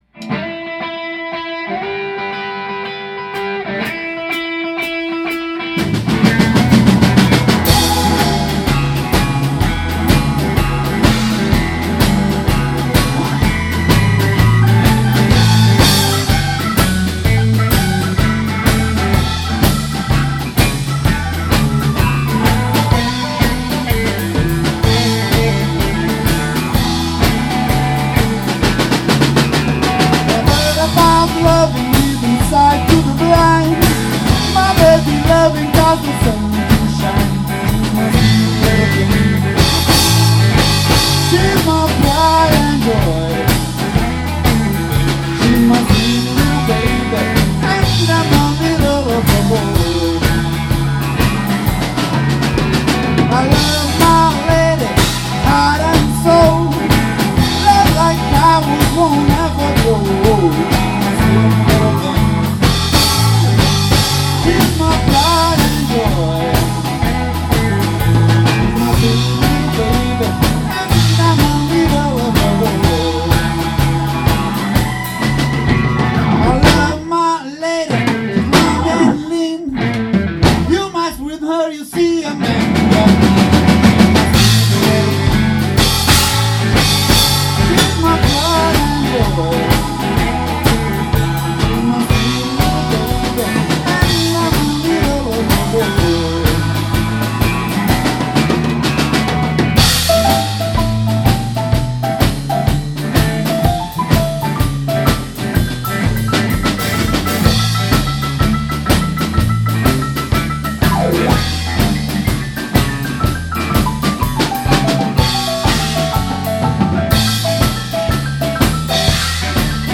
is a brazilian rock'n roll band
We are a vintage rock'n roll band.
an audio file from a song we played in a recent